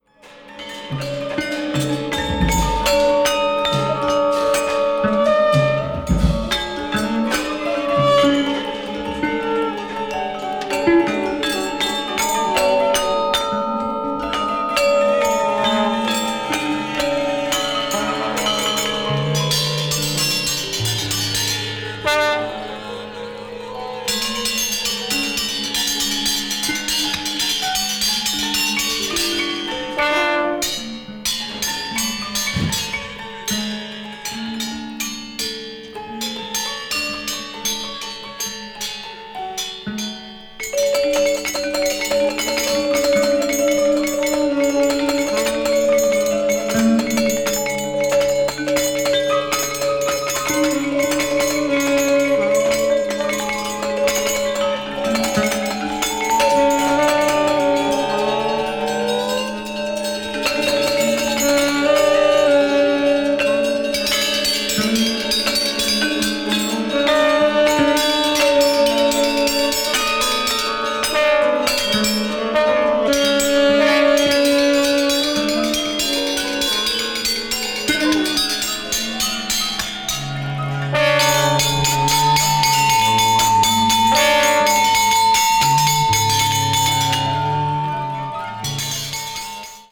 ethnic jazz
guitarist
Featuring gamelan and other ethnic instruments